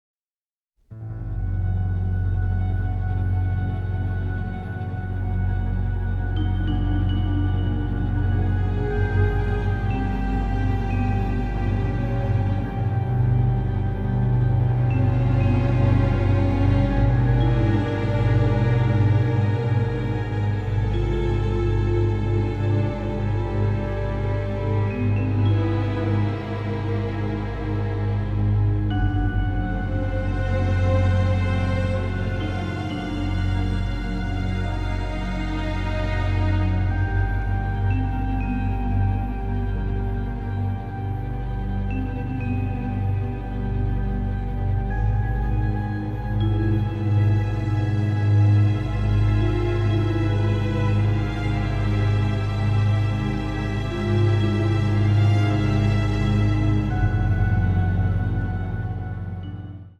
suspense score